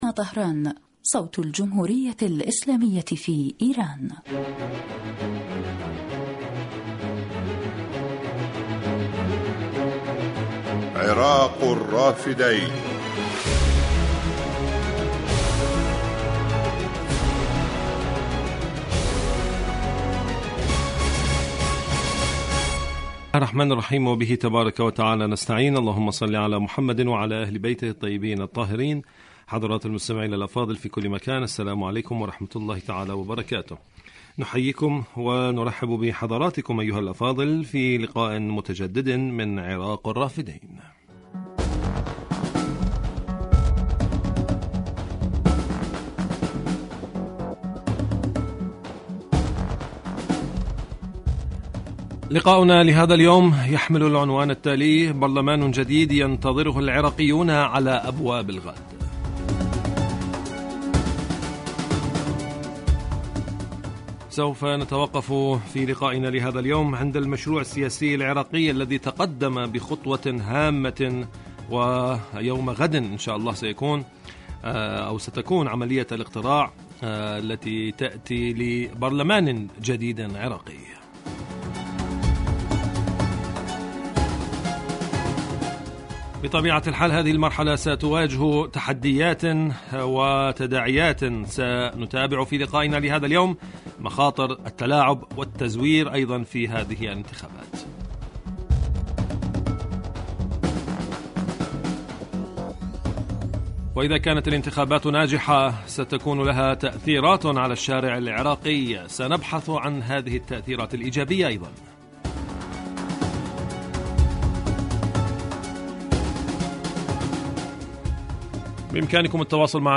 برنامج حي يتناول بالدراسة والتحليل آخرالتطورات والمستجدات على الساحة العراقية وتداعيات على الإقليم من خلال استضافة خبراء سياسيين ومداخلات للمستمعين عبر الهاتف.
يبث هذا البرنامج على الهواء مباشرة أيام السبت وعلى مدى نصف ساعة